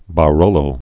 (bä-rōlō, bə-)